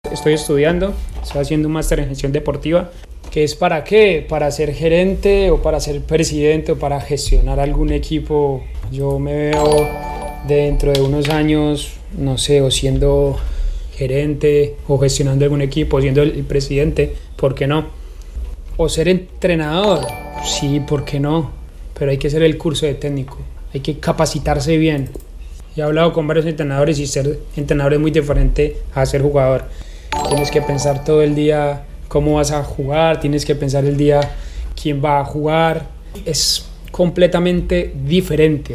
(James Rodríguez en su cuenta de Twitch)